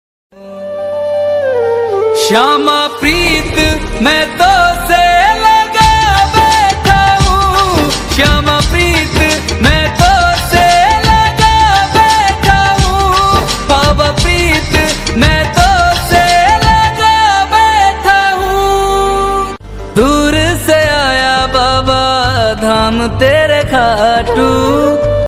bhakti ringtone download mp3